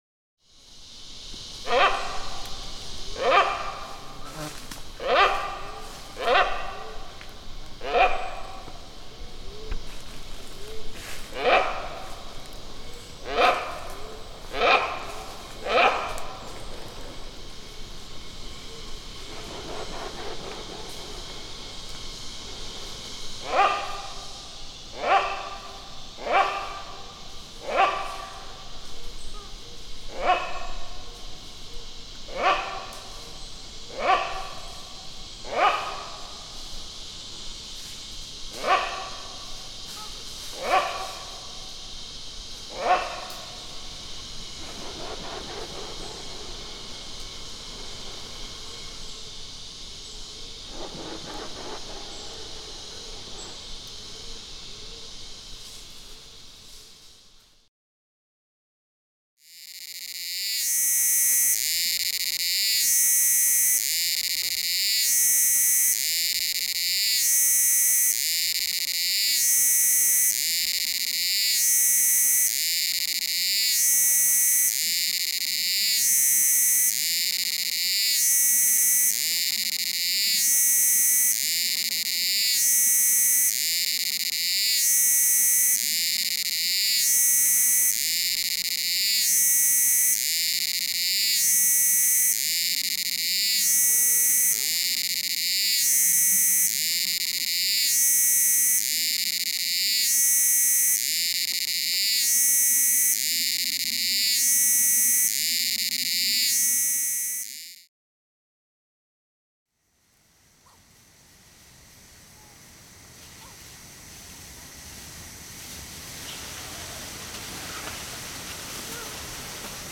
Tangkoko Nature Reserve - Sulawesi, Indonesia
Category 🌿 Nature
ape Asia bill bird bird-call birds black bugs sound effect free sound royalty free Nature